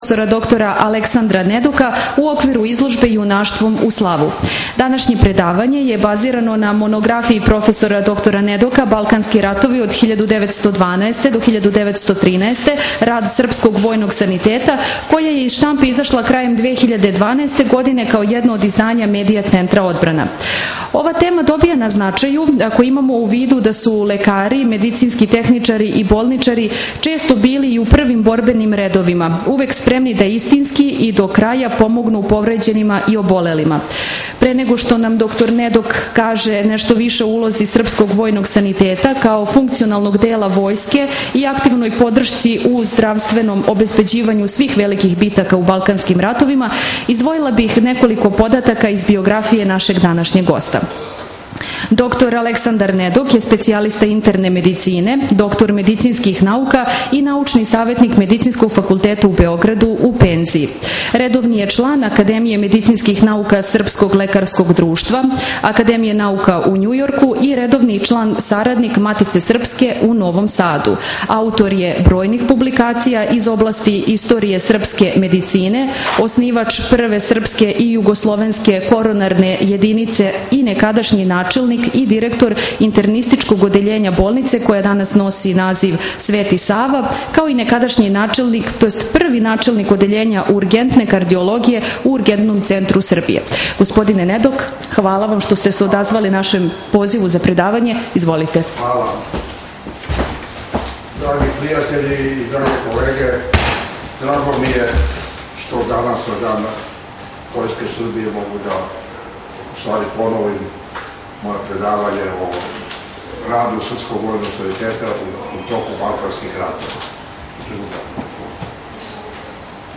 The lecture is a part of the accompanying programme of the exhibition "Through Heroism to Fame". DOCUMENTS Lecture (79 min; 36 MB) mp3